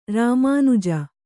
♪ rāmānuja